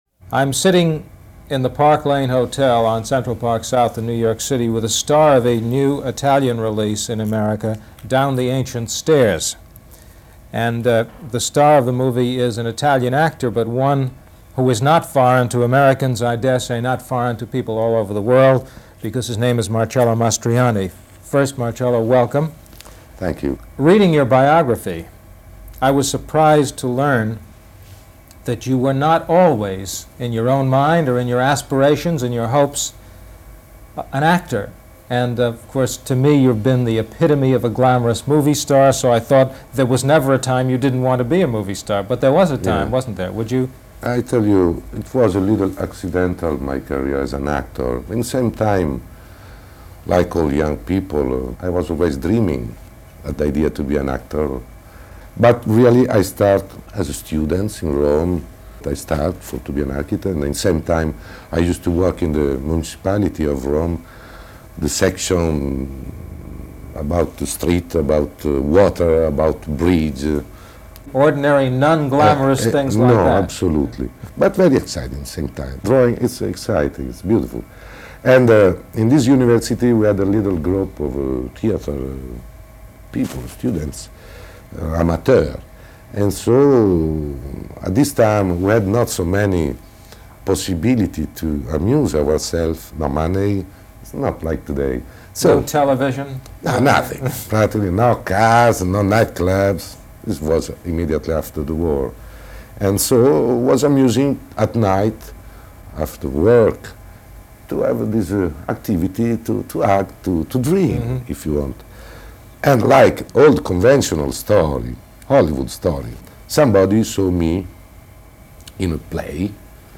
Mastroianni is interviewed